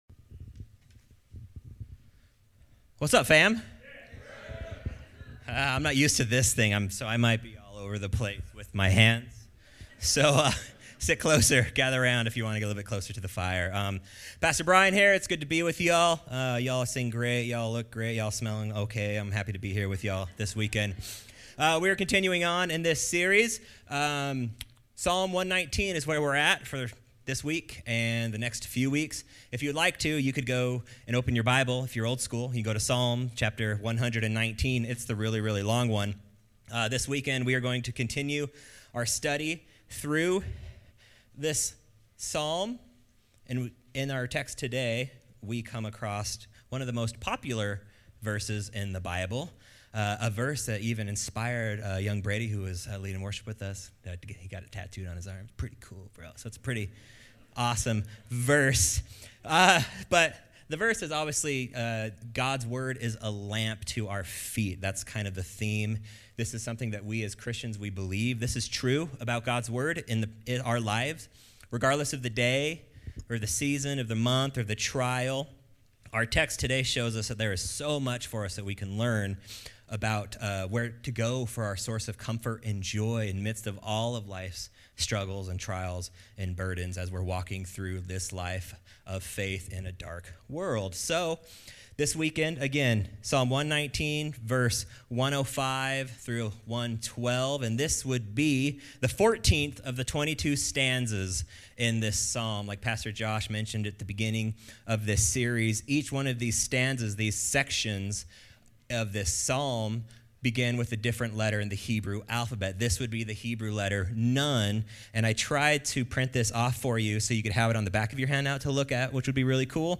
How does a Christian stay in the light of God and His Word while constantly distracted, tempted, and lured into the things of this world? In this sermon, we will look at how God's Word provides light, comfort, and joy even when everything around us seems dark.